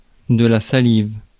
Ääntäminen
Ääntäminen France: IPA: [sa.liv] Haettu sana löytyi näillä lähdekielillä: ranska Käännös Konteksti Substantiivit 1. saliva fysiologia 2. spittle fysiologia Suku: f .